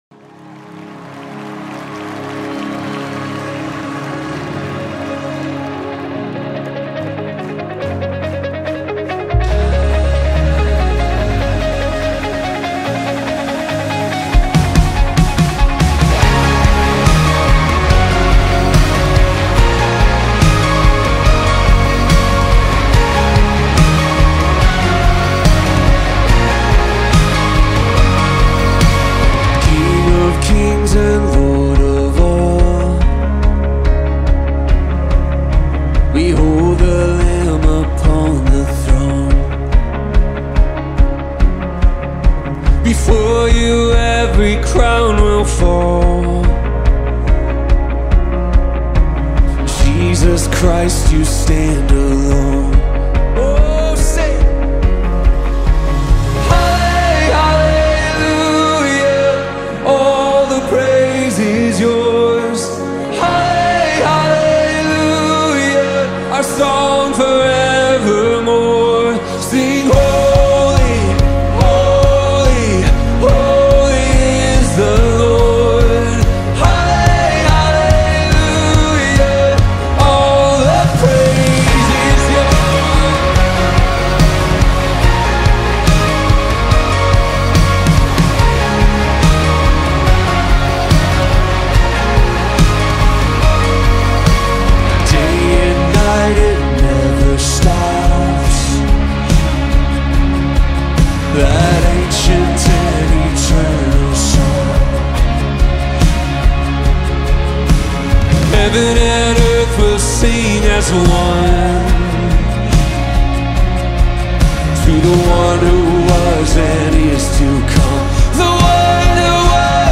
128 просмотров 121 прослушиваний 18 скачиваний BPM: 143